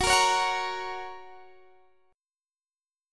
Listen to F# strummed